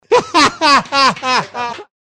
Play, download and share risa_tv_chilena original sound button!!!!
risa-tv-chilena.mp3